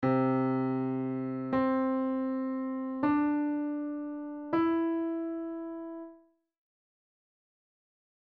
I'm going to play C then up an octave and C
then 2 white notes of my choice